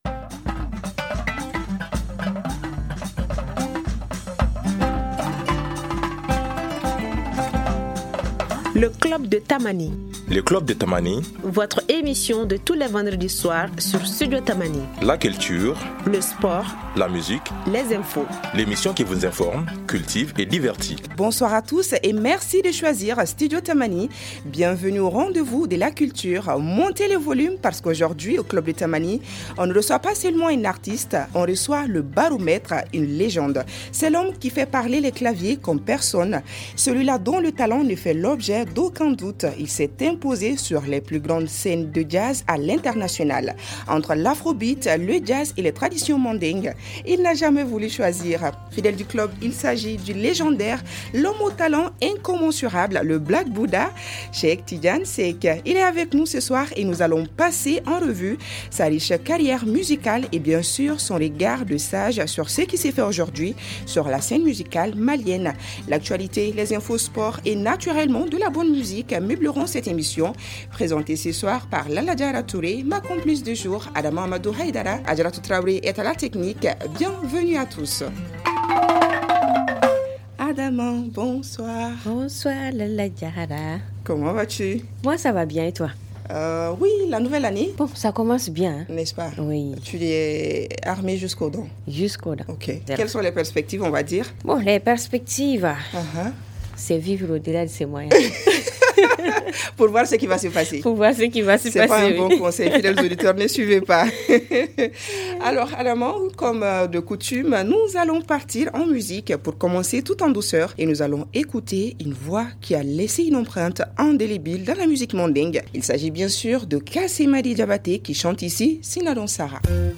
Légende du jazz international et multi-instrumentiste, Cheick Tidiane Seck est l’invité du Club de Tamani de ce vendredi. Entre héritage mandingue et modernité afrobeat, il revient sur son immense carrière et partage son regard de sage sur l’évolution de la musique malienne.